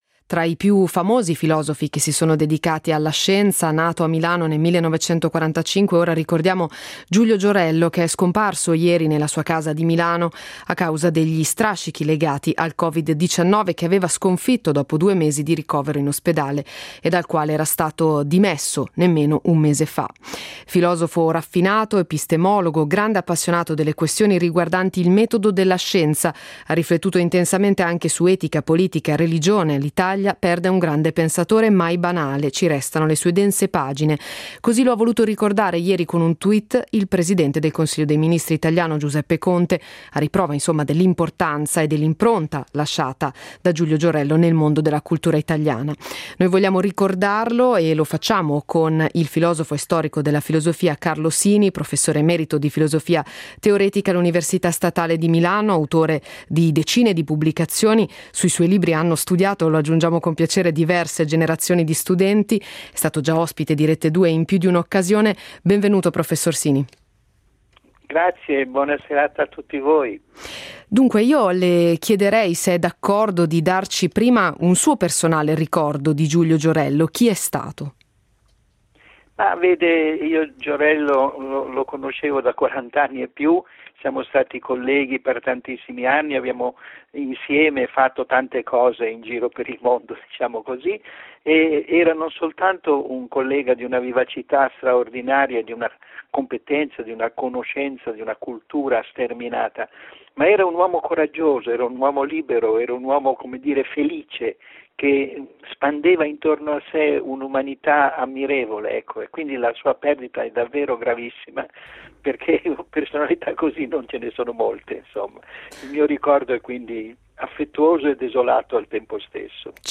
Tra i maggiori epistemologi degli ultimi decenni, nato a Milano nel 1945, Giulio Giorello è morto il 15 giugno 2020, nella sua casa di Milano, a causa degli strascichi legati al Covid-19. Vogliamo ricordarlo con il filosofo e storico della filosofia Carlo Sini.